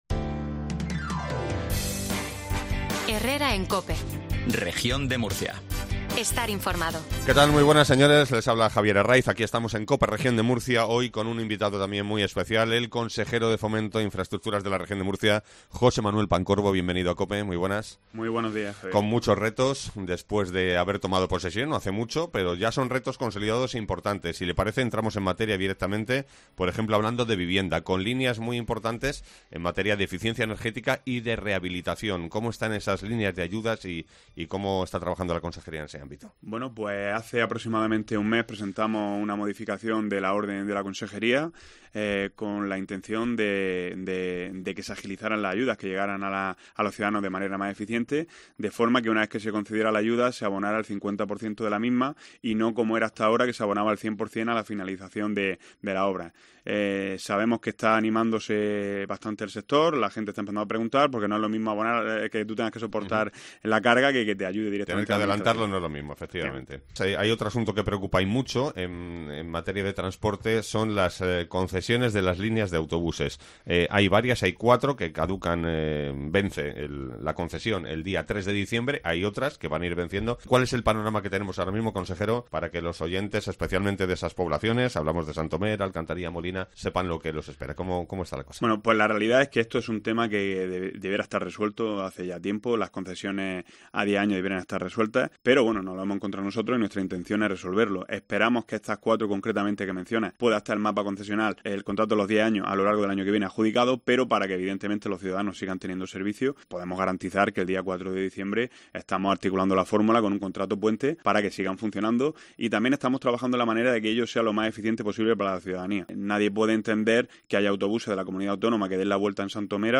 ENTREVISTA
El consejero de Fomento confirma en COPE Murcia que en breve se sacarán nuevas concesiones más eficaces.